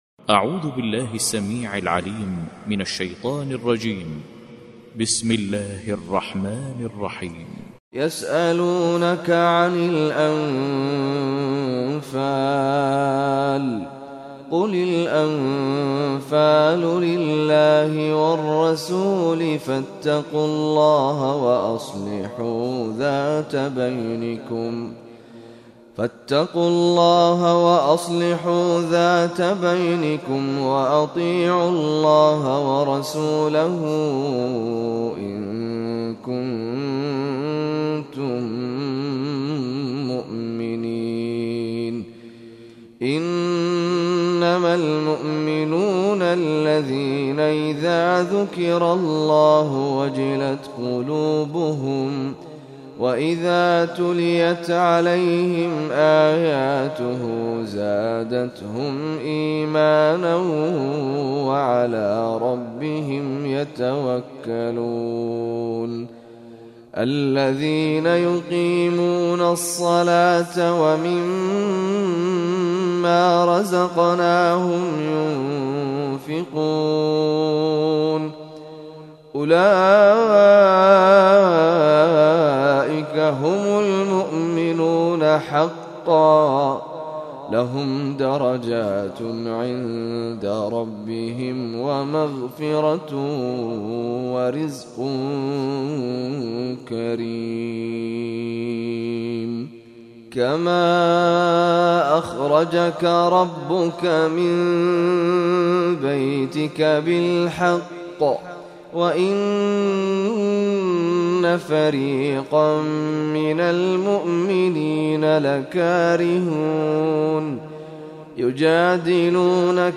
Surah Al-Anfal, listen or play online mp3 tilawat / recitation in Arabic